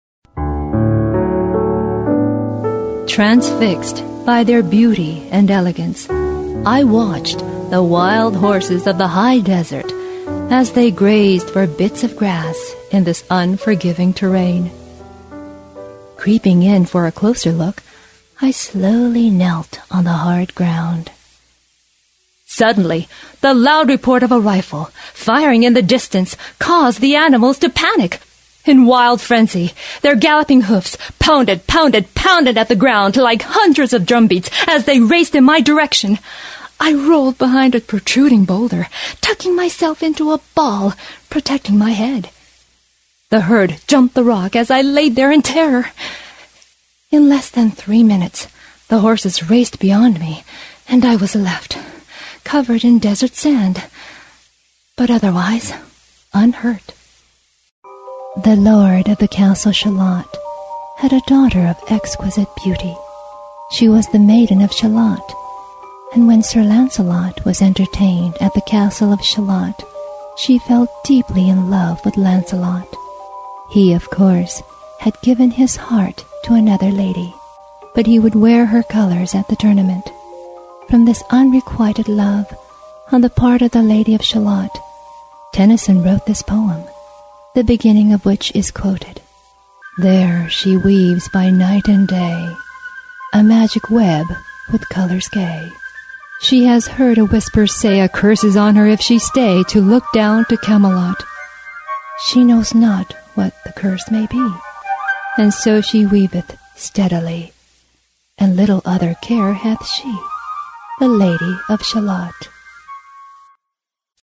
音声サンプル
日本語・英語ともに堪能で、即興性にも優れたマルチな声質の持ち主です。
ソプラノ・アルト
英語(アメリカネイティブ)